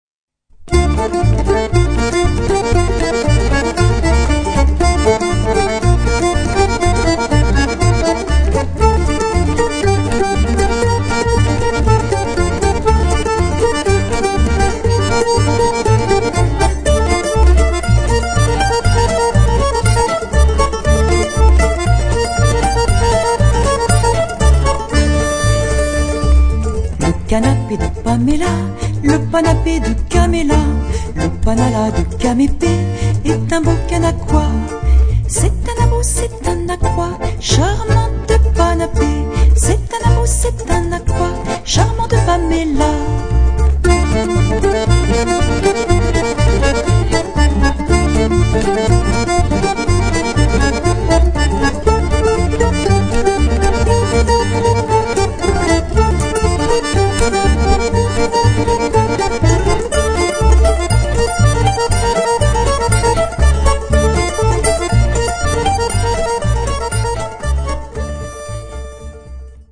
voix et accordéon
swing musette